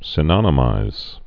(sĭ-nŏnə-mīz)